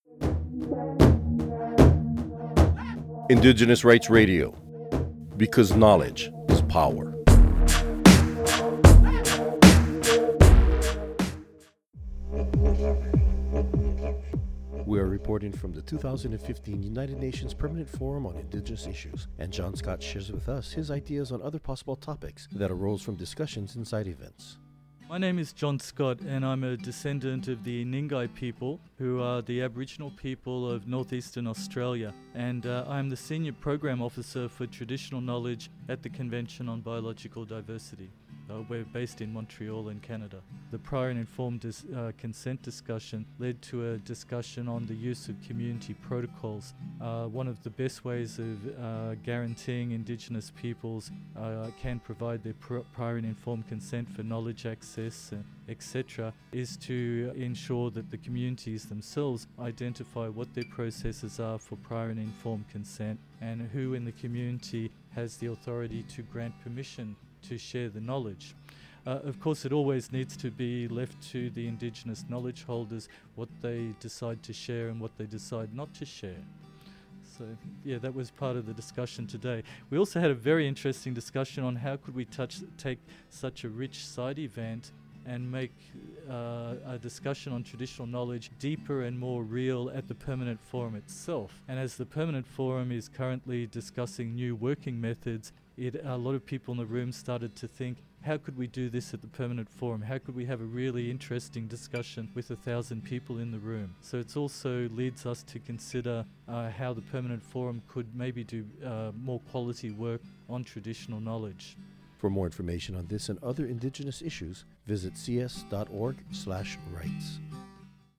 Recording Location: UNPFII 2015
Type: Interview